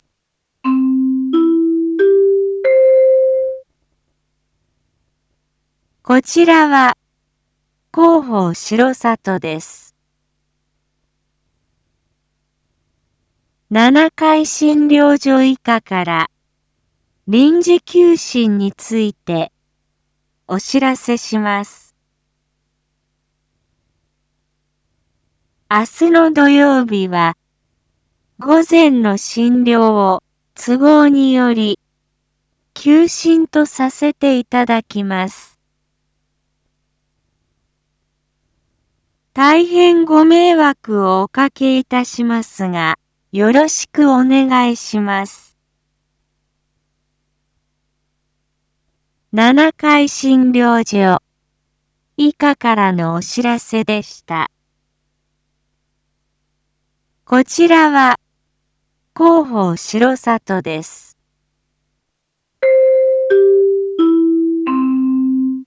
一般放送情報
Back Home 一般放送情報 音声放送 再生 一般放送情報 登録日時：2025-04-11 19:01:10 タイトル：R7.4.11七会診療所医科臨時休診 インフォメーション：こちらは広報しろさとです。